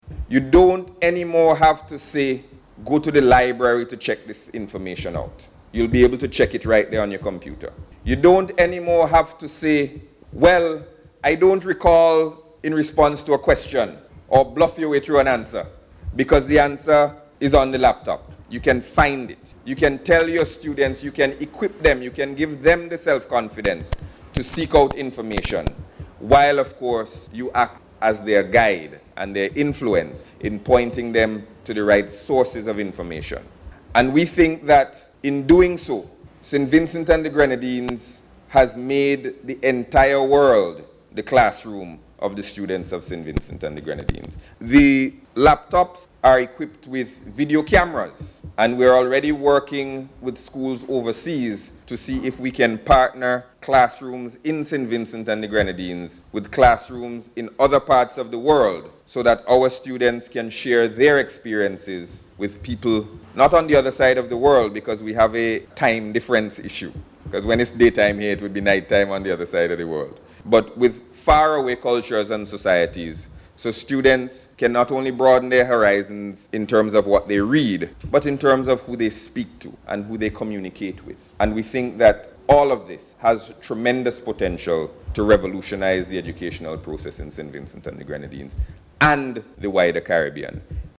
That’s according to Minister responsible for Information and Communications Technology, ICT, Camillo Gonsalves, as he addressed this week’s launch of a one-month ICT training programme for Secondary School Teachers.